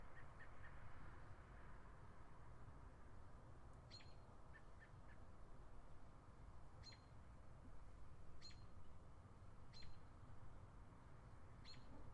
清晨鸟叫声
描述：清晨，一只鸟正在靠近阳台处唱歌。 我准备好录音机安静地录音，后期做了噪音去除和过滤来摆脱远处的交通声，添加了一个轻微的混响听起来更空灵。
标签： 动物 唱歌 鸟叫声 小鸟
声道立体声